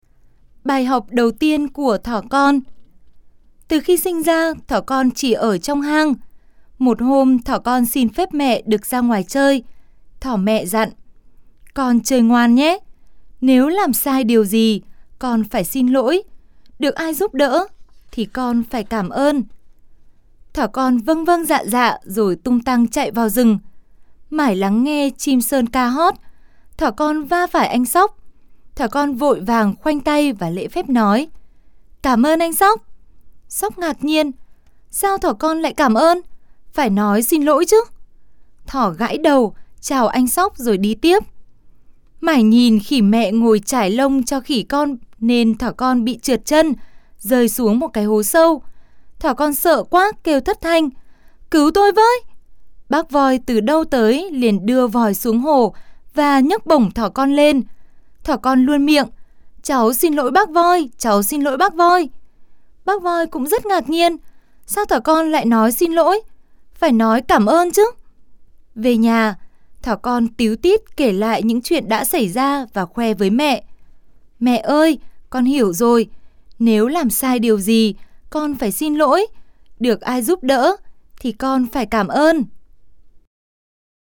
Sách nói | Bài học đầu tiên của thỏ con